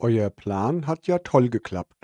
A free to use, offline working, high quality german TTS voice should be available for every project without any license struggling.